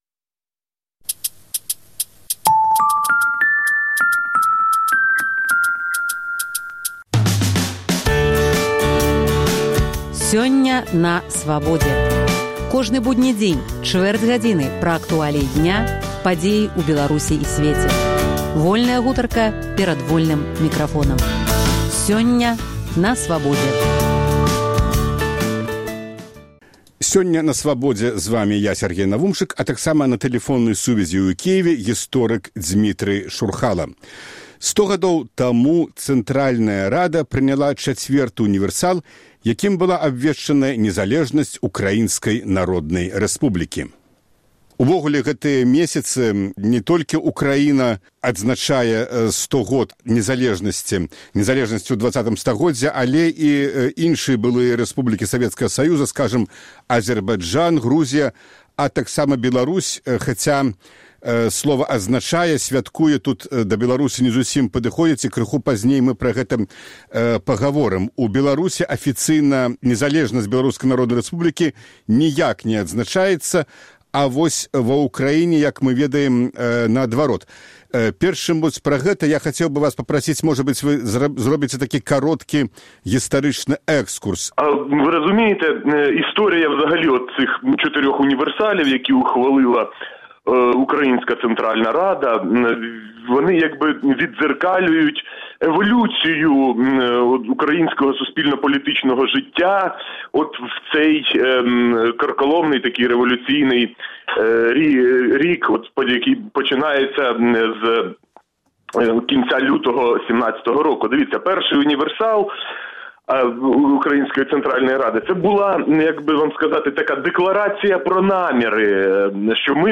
22 студзеня Цэнтральная Рада Ўкраінскай Народнай Рэспублікі прыняла Чацьверты ўнівэрсал, якім была абвешчаная незалежнасьць УНР. Госьць перадачы - гісторык